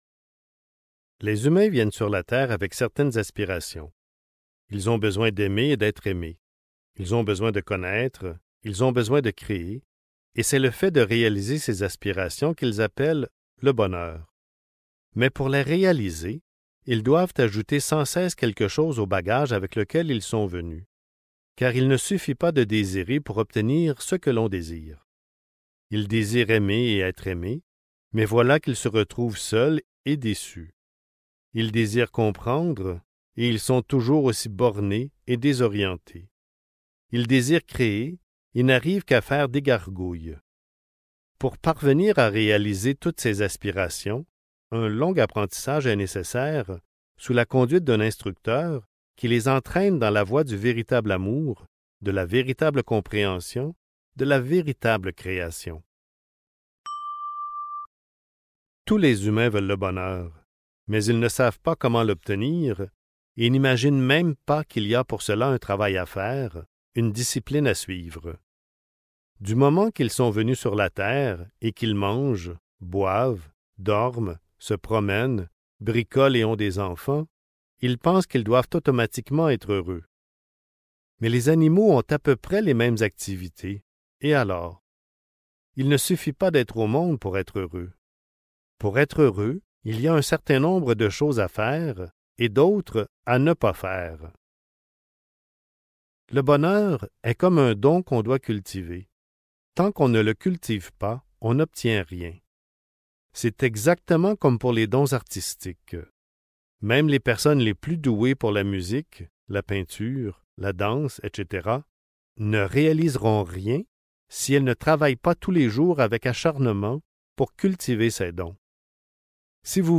Les semences du bonheur (Livre audio | CD MP3) | Omraam Mikhaël Aïvanhov